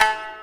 Hits